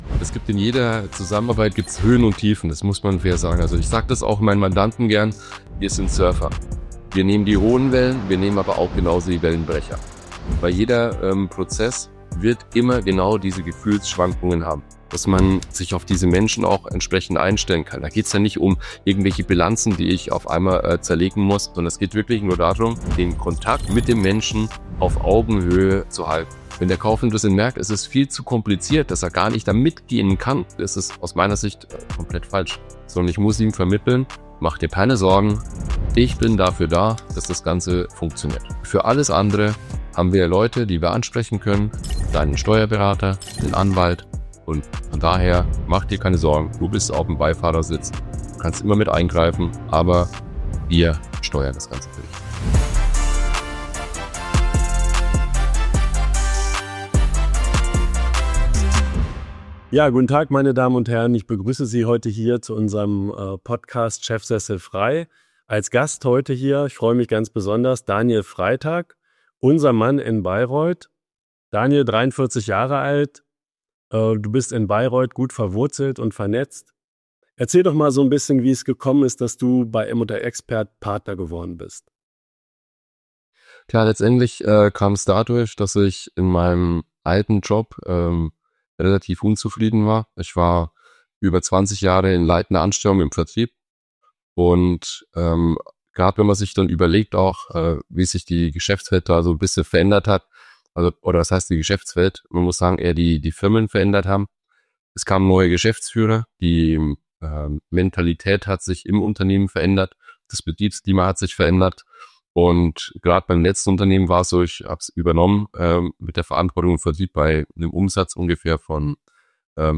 Ein tiefgehendes Gespräch über Verantwortung, Psychologie, Marktmechanismen und das richtige Timing im Mittelstand – mit klaren Einblicken in die tägliche Arbeit eines M&A-Beraters.